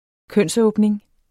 Udtale [ ˈkœnˀsˌɔːbneŋ ]